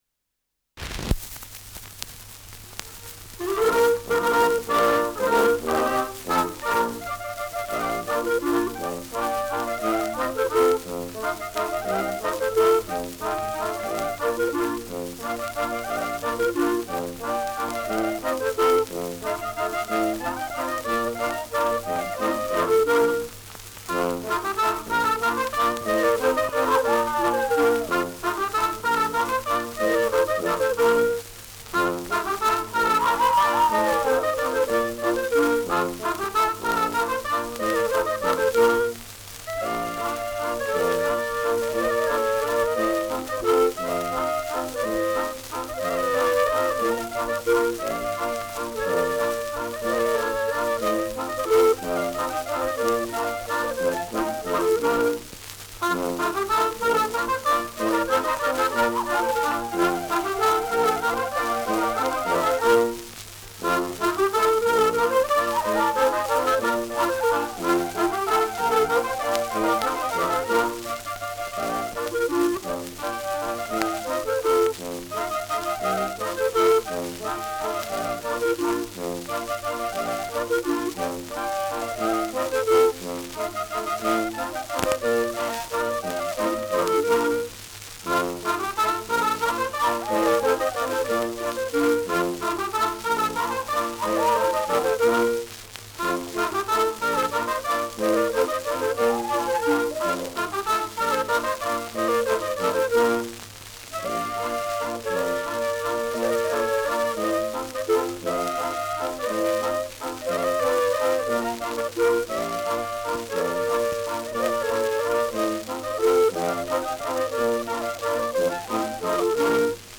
Schellackplatte
Tonrille: Kratzer 5-10 Uhr Leicht
präsentes Rauschen
[unbekanntes Ensemble] (Interpretation)
Enthält bekannte Vierzeilermelodien. Mit Juchzern.